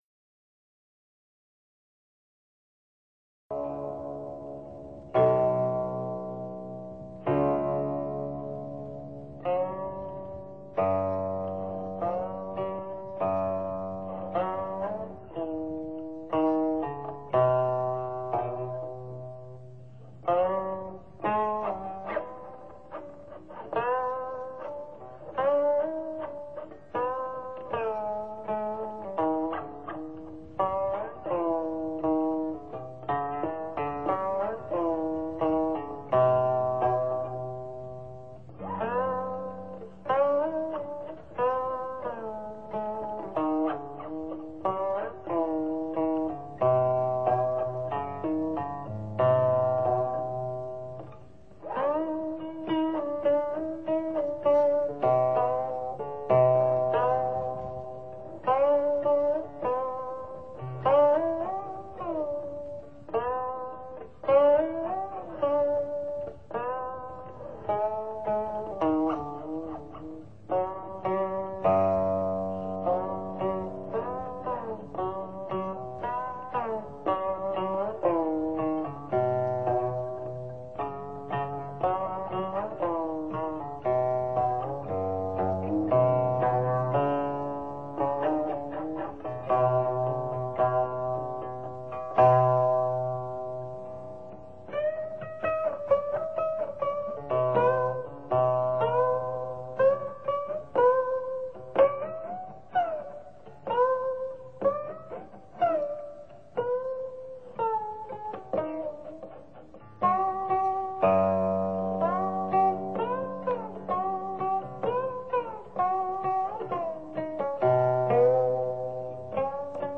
这套录音在50年代。